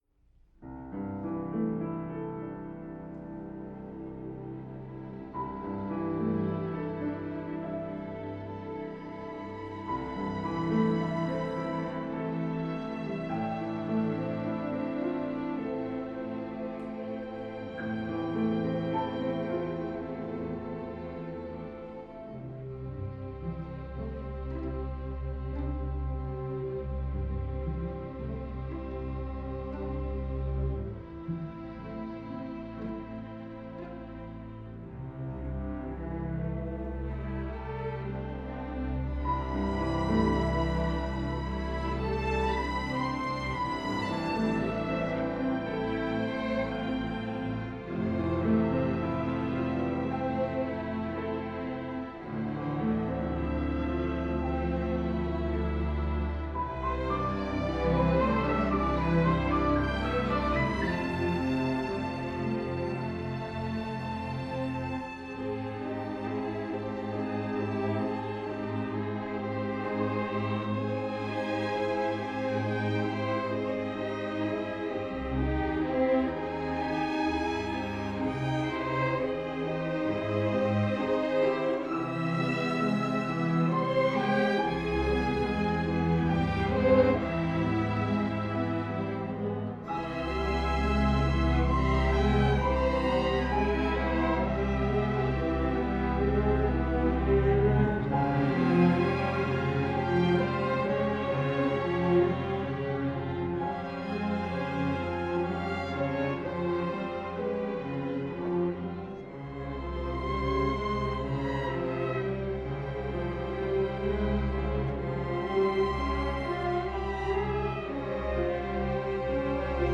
String Orchestra and Piano
Premiere Performance